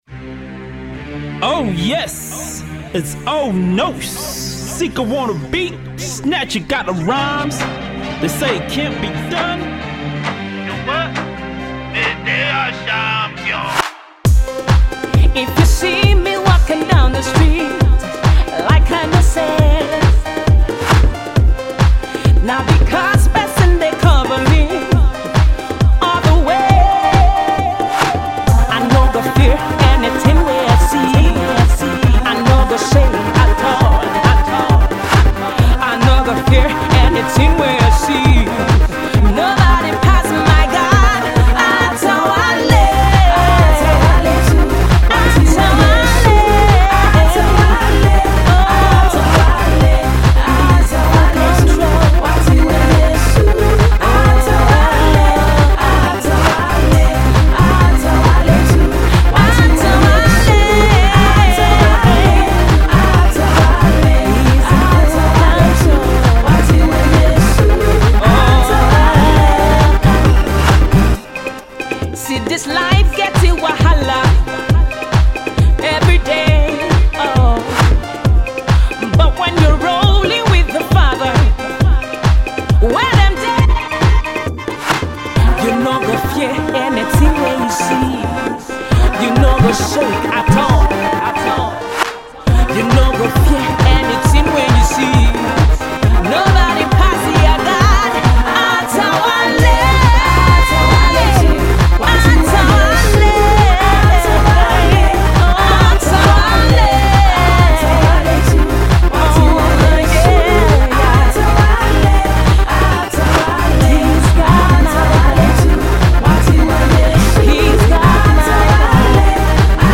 It’s definitely a Gospel song you can really dance to.